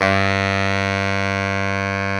SAX B.SAX 12.wav